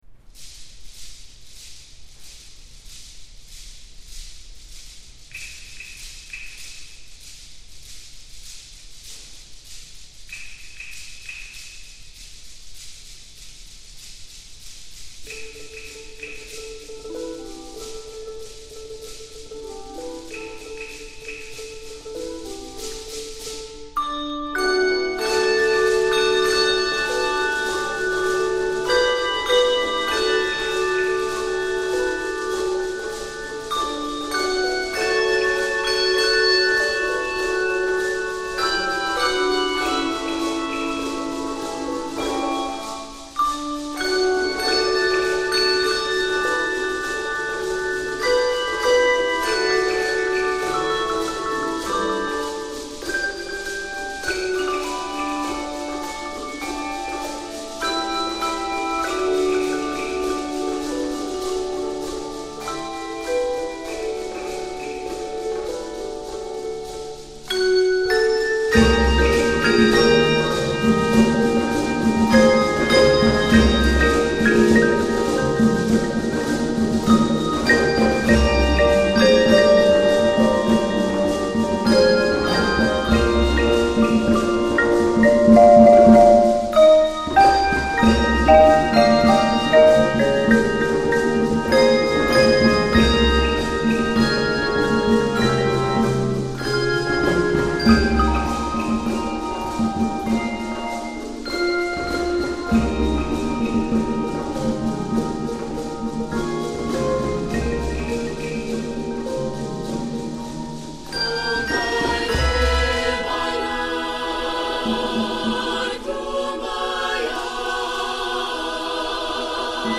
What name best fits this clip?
Voicing: Percussion